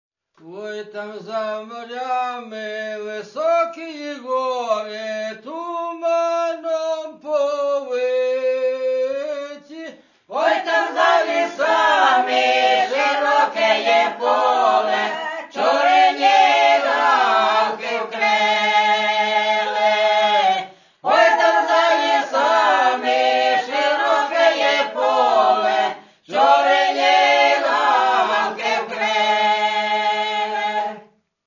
Традиційні ліричні пісні Полтавщини.